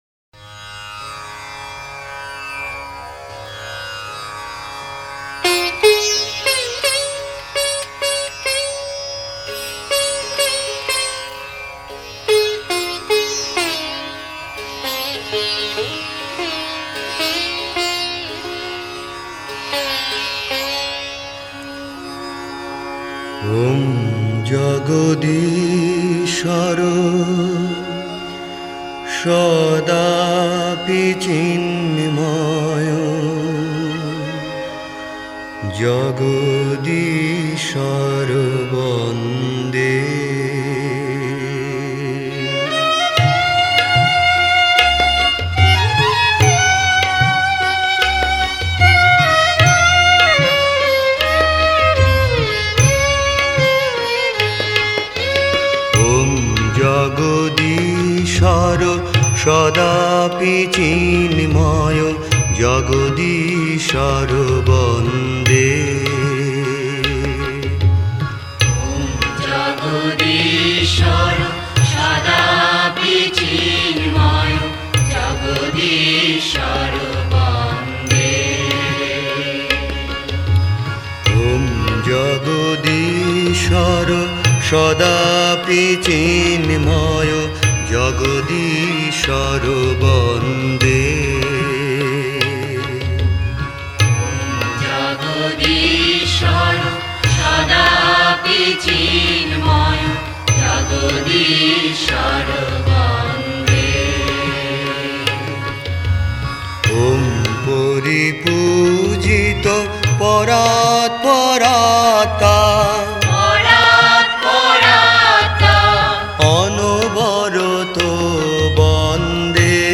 Directory Listing of mp3files/Bengali/Devotional Hymns/Bhajons/ (Bengali Archive)